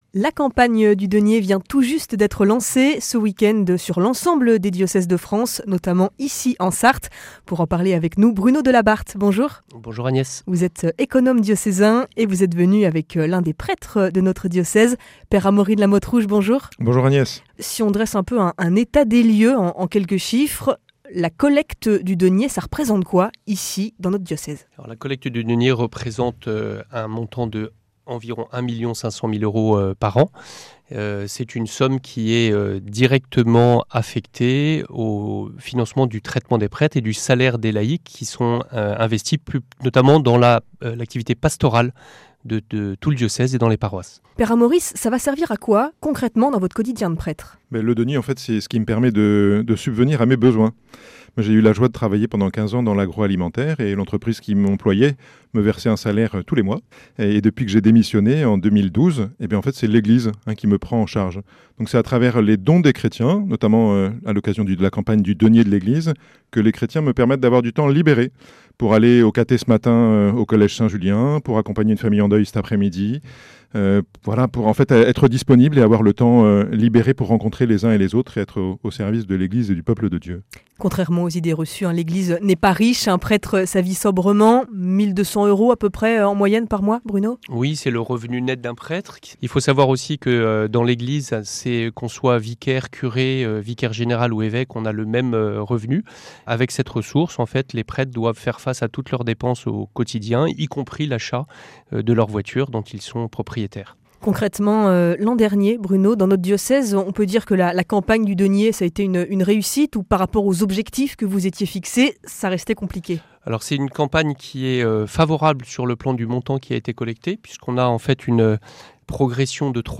au micro de RCF Sarthe